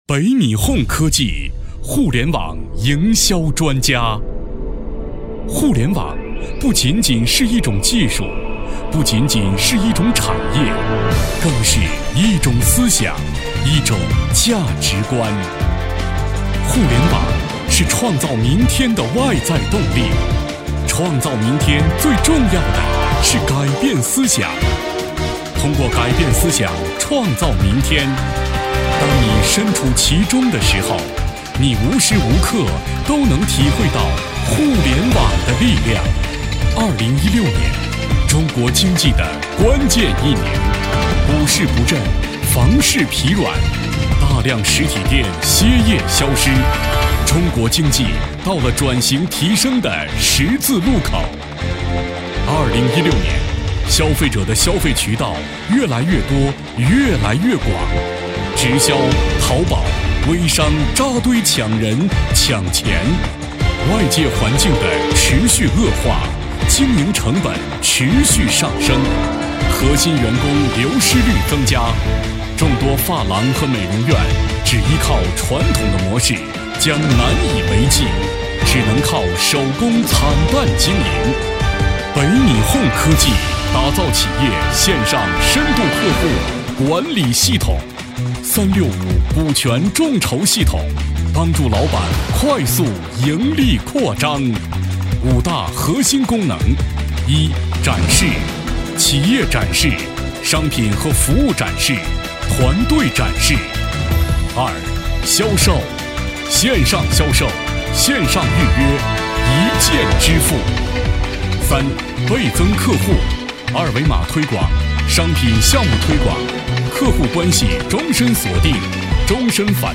人物专题
特 点：大气浑厚 稳重磁性 激情力度 成熟厚重